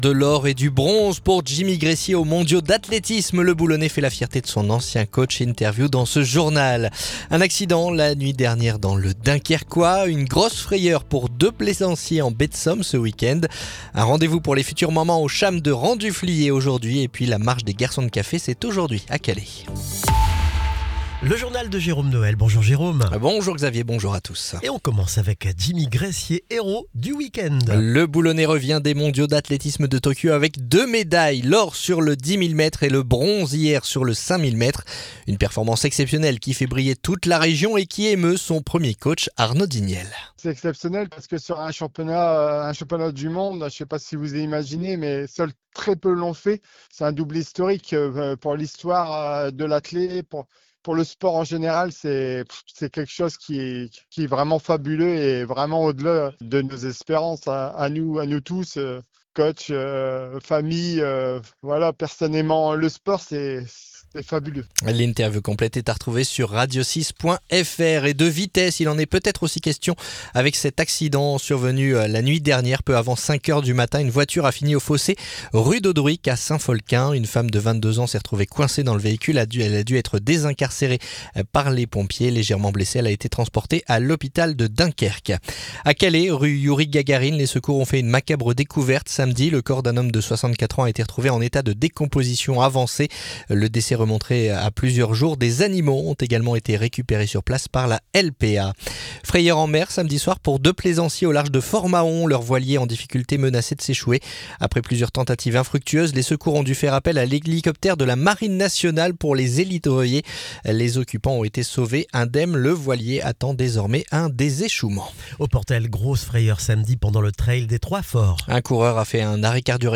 Le journal du lundi 22 septembre